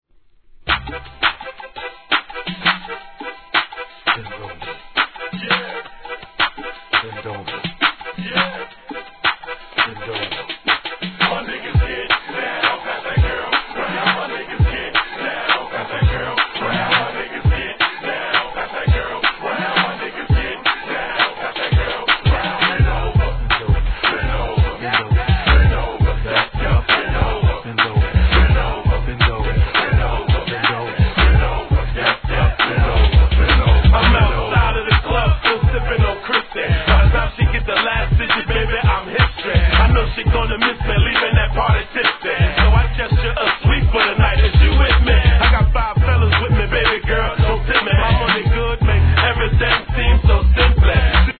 1. G-RAP/WEST COAST/SOUTH
疾走するBEATに南部特有のチキチキ感がたまらないインディー物!